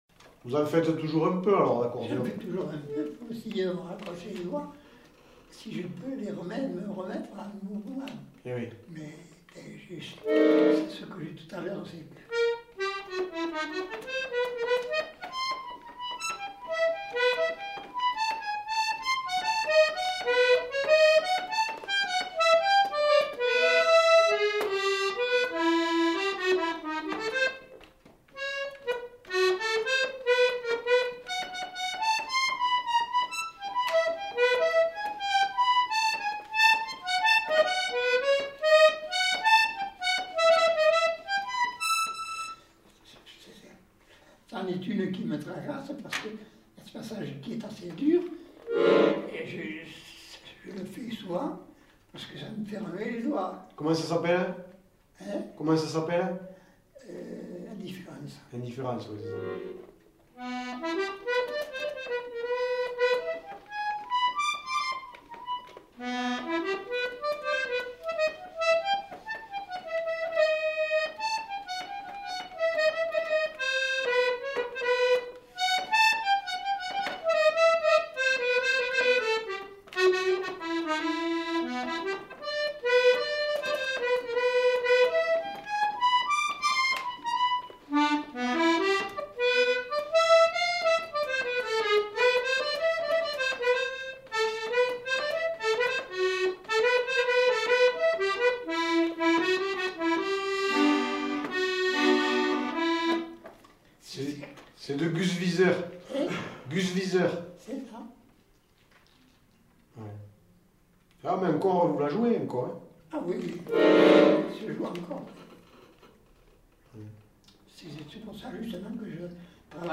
Aire culturelle : Quercy
Lieu : Souillac
Genre : morceau instrumental
Instrument de musique : accordéon
Danse : valse